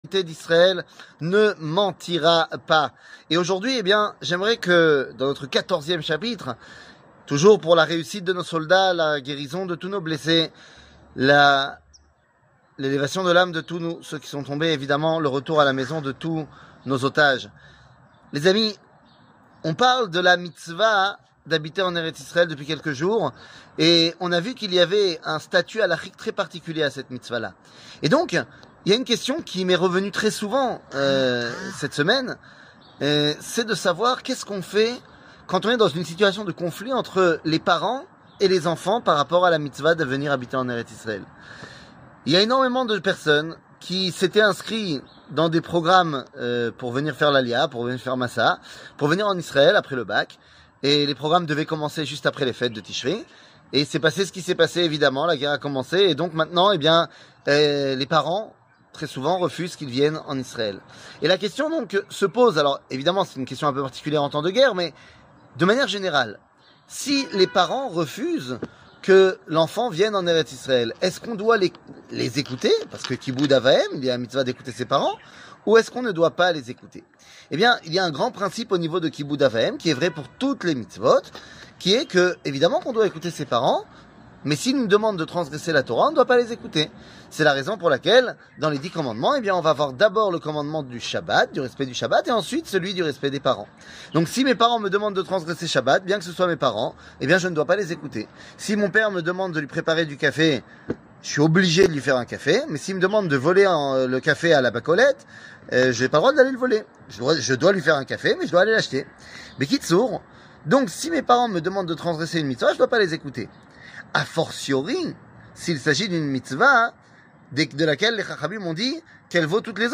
L'éternité d'Israel ne mentira pas ! 14 00:06:03 L'éternité d'Israel ne mentira pas ! 14 שיעור מ 25 אוקטובר 2023 06MIN הורדה בקובץ אודיו MP3 (5.54 Mo) הורדה בקובץ וידאו MP4 (9.23 Mo) TAGS : שיעורים קצרים